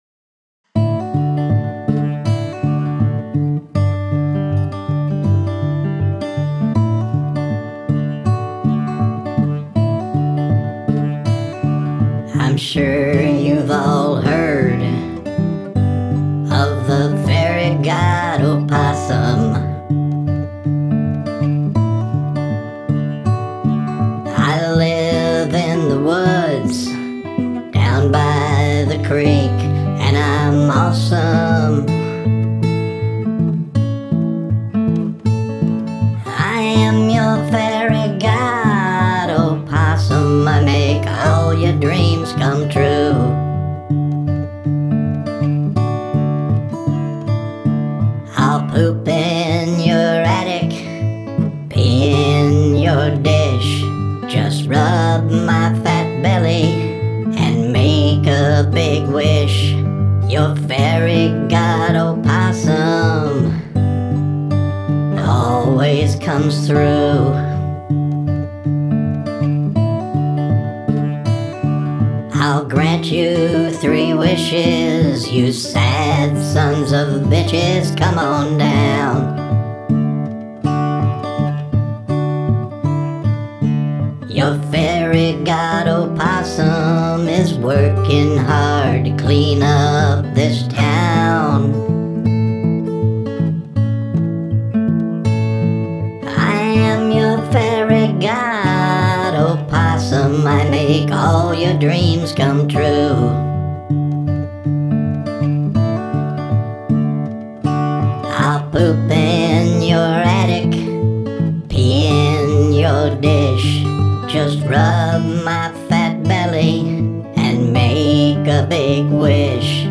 Love your voice and the guitar work here is very cool too!
Rich guitar and fun voice filter.
Really nice guitar work on this.